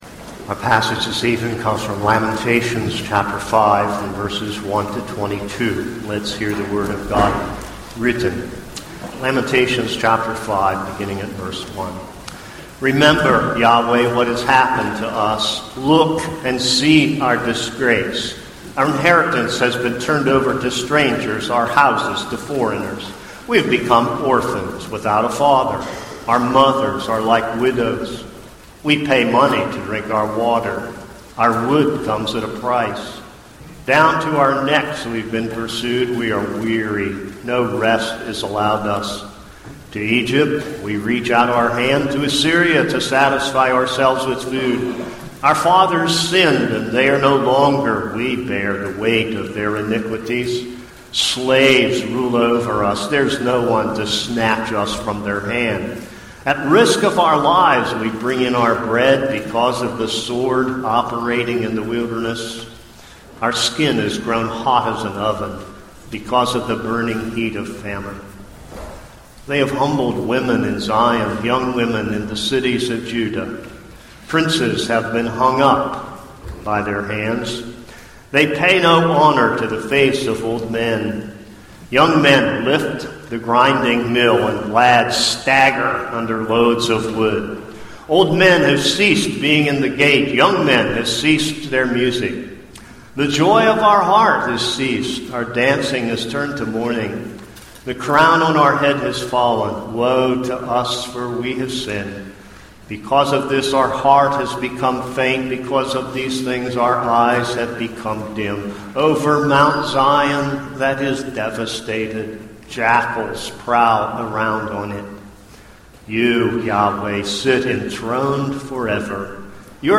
This is a sermon on Lamentations 5.